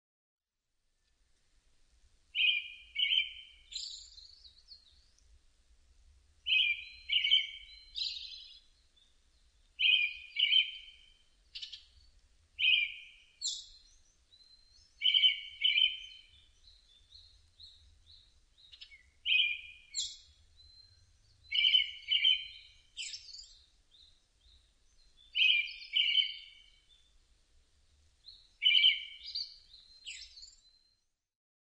アカハラ　Turdus chrysolausツグミ科
日光市稲荷川中流　alt=730m  HiFi --------------
Mic.: Sound Professionals SP-TFB-2  Binaural Souce
他の自然音：　 センダイムシクイ・シジュウカラ